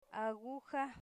Phonological Representation a'guxa